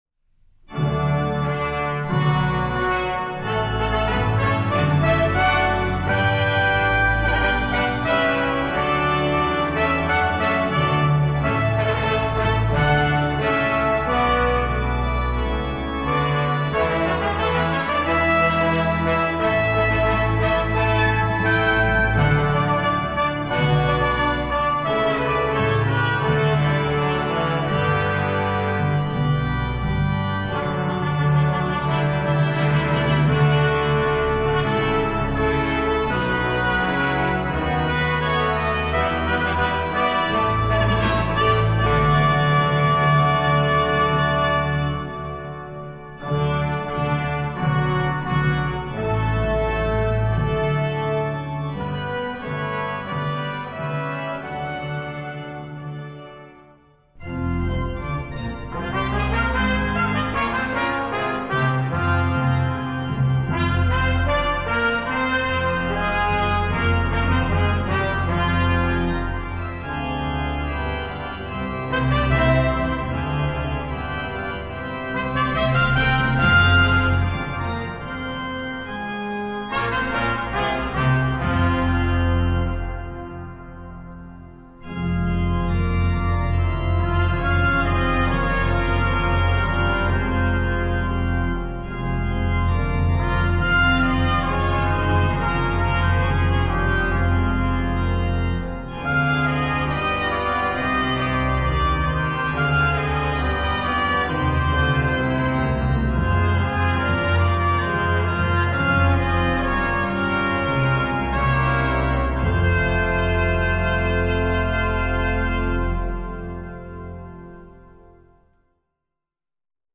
Voicing: SATB with Descant